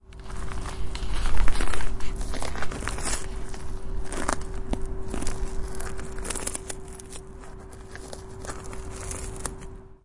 滑翔伞 " 滑翔伞起飞了
描述：滑翔伞的Lofi DSLR音频从大约15英尺远的地方起飞。
Tag: 关闭 滑翔伞 跳伞